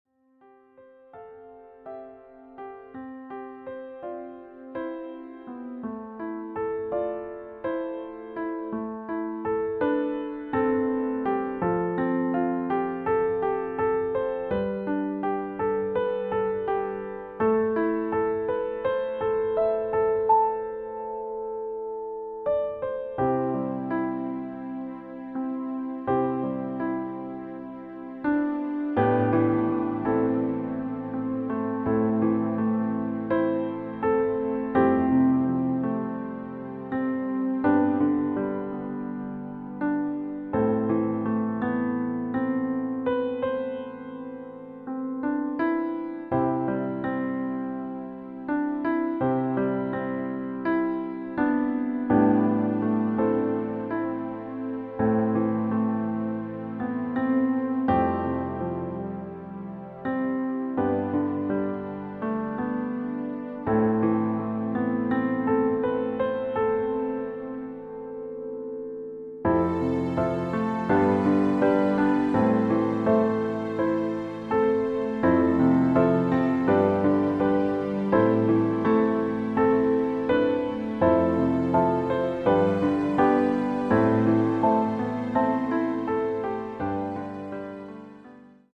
• Art: Klavier Streicher Version
• Das Instrumental beinhaltet NICHT die Leadstimme
• Das Instrumental enthält KEINEN Text
Klavier / Streicher